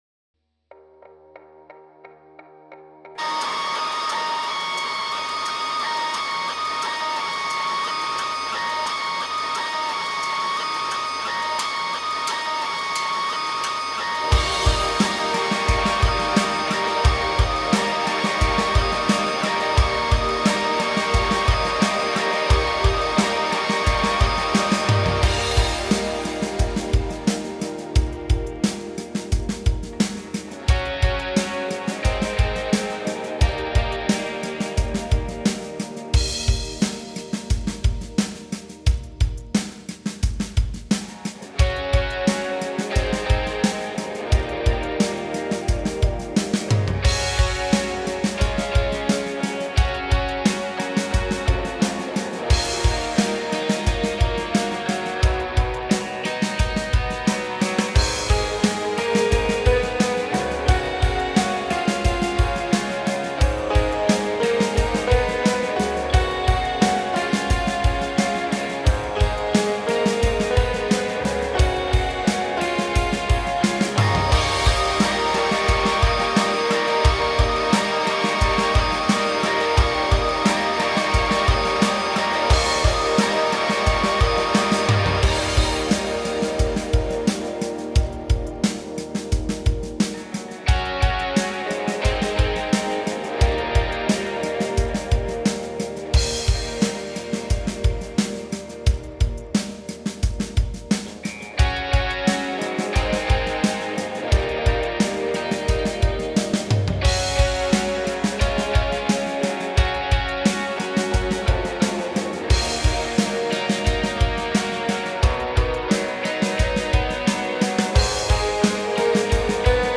Without vocals